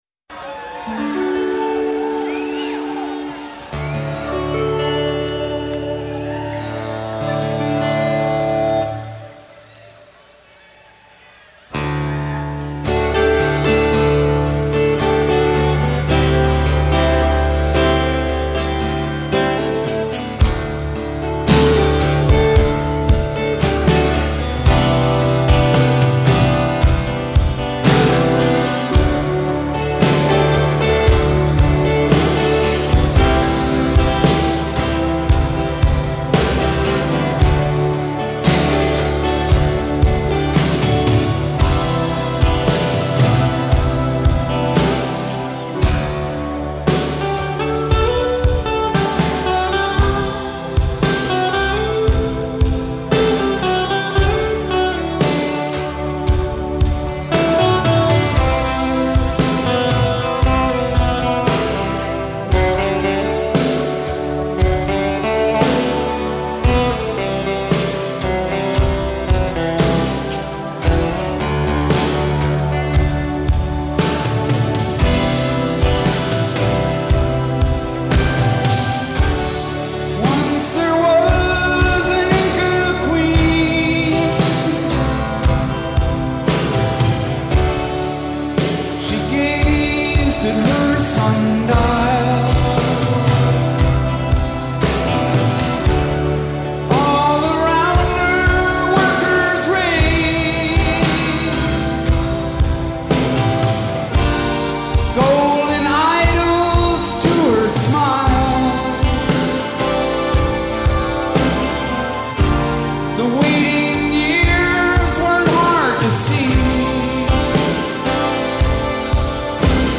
sold-out Cow Palace audience in November 1986